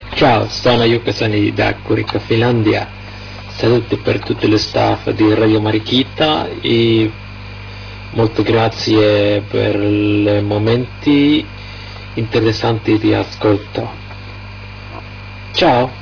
with Tape inclused of reception report from